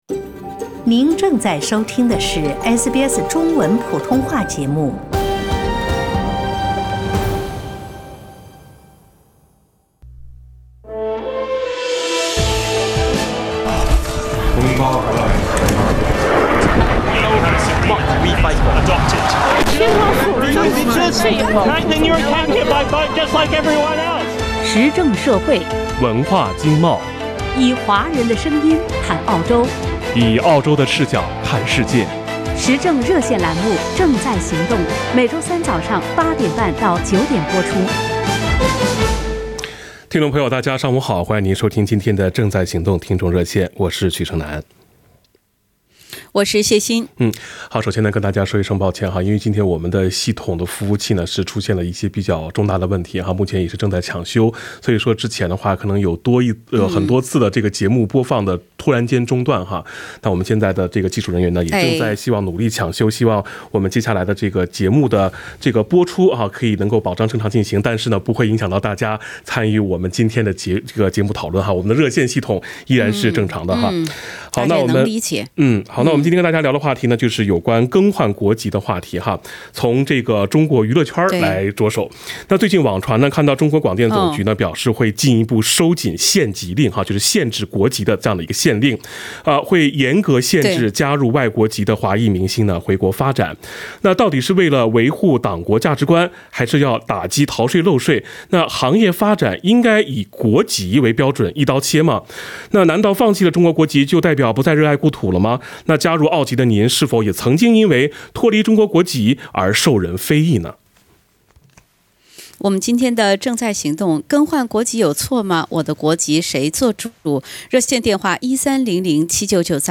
难道放弃中国国籍就代表不再热爱故土，加入澳籍的您曾经因为脱离中国国籍而受人非议吗？（点击封面图片，收听热线回放）